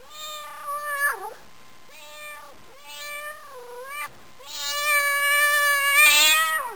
Cat